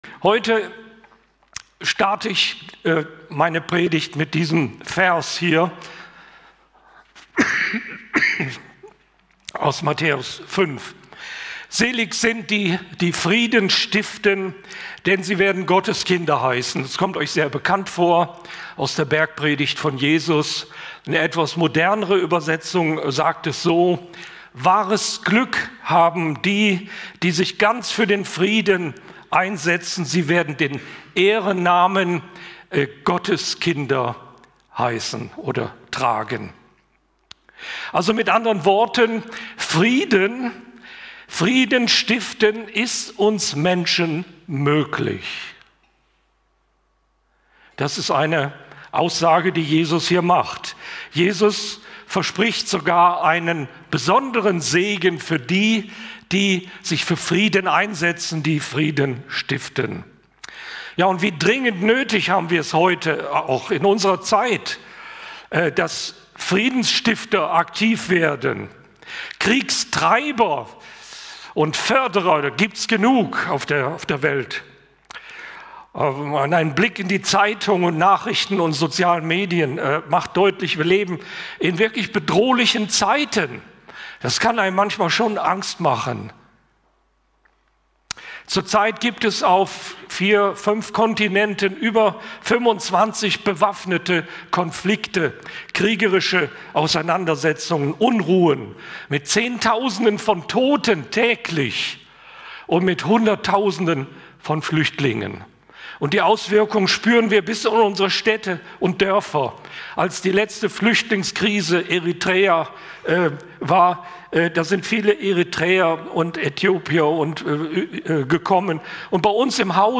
Gottesdienst_-Predigt-nach-Micha-4-1-5.mp3